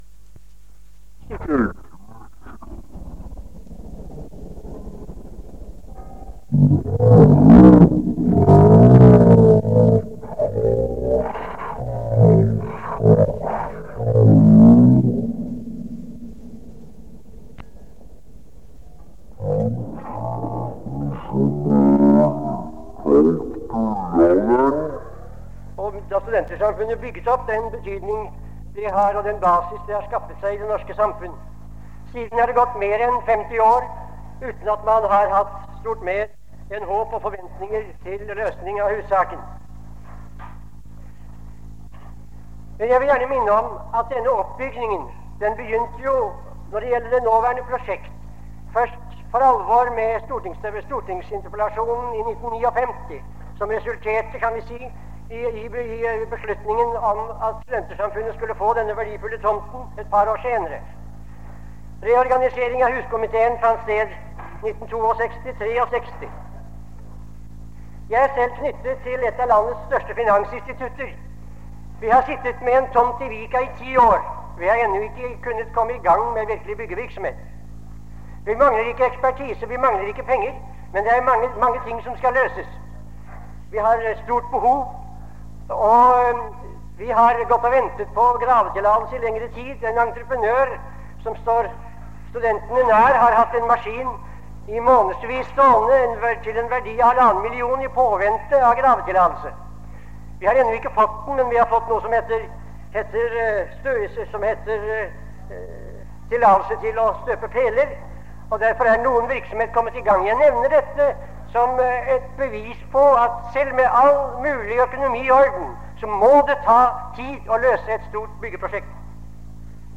Det Norske Studentersamfund, Generalforsamlinger, Ekstraordinær generalforsamling, 10.12.1965